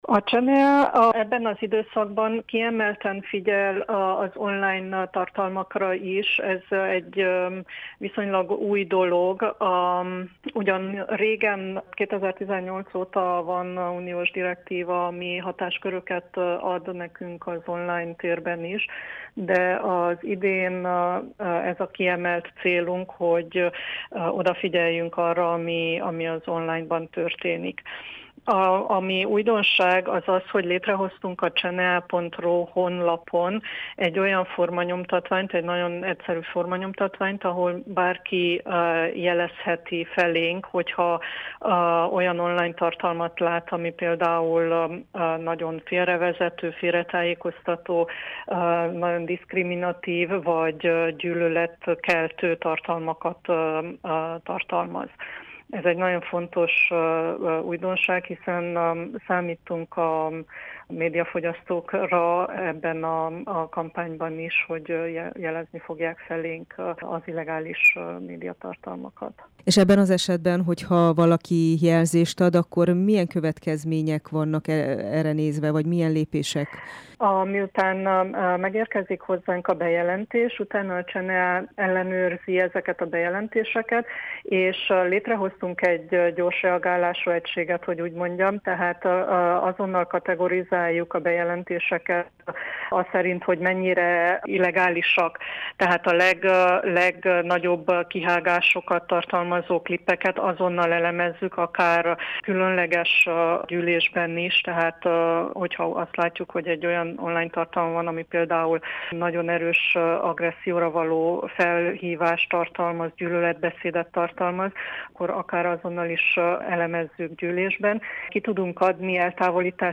Borsos Orsolyát, az Országos Audióvizuális Tanács tagját kérdezte